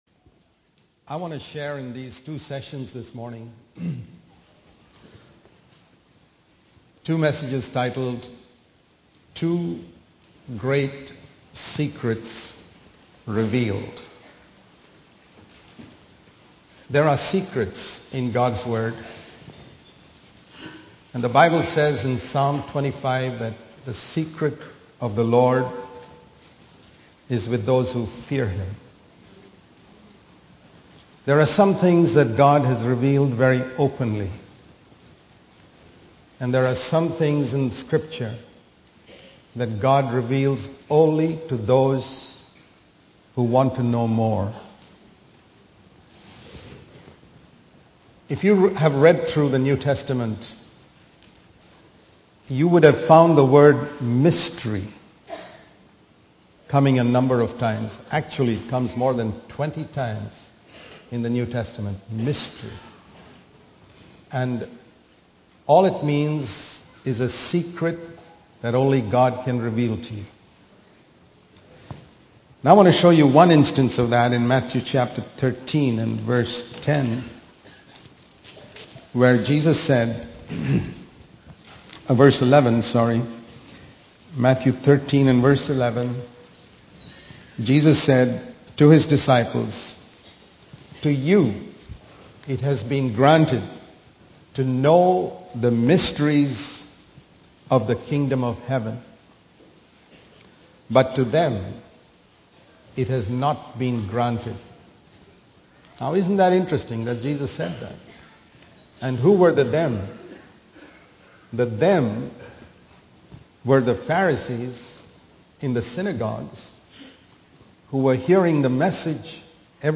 In this sermon, the speaker emphasizes the importance of Jesus Christ being our hero and role model. He compares young people's admiration for sports stars to the way we should strive to be like Christ. The speaker highlights the difference between the Old Testament, which is focused on commandments, and the New Testament, which calls us to follow Jesus.